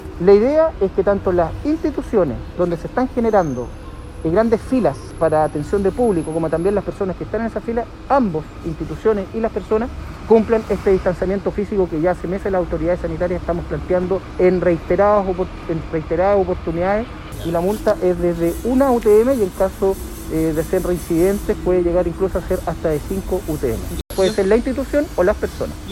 Así dio cuenta el alcalde de la capital penquista, Álvaro Ortíz.